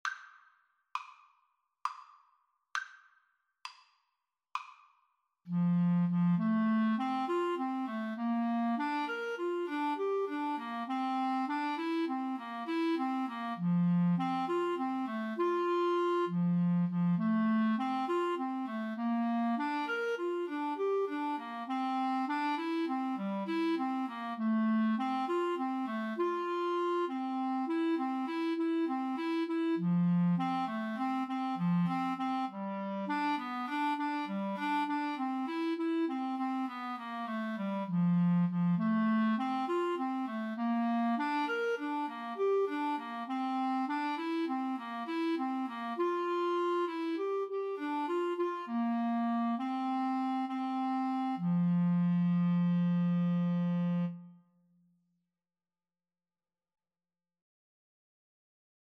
Moderato
9/8 (View more 9/8 Music)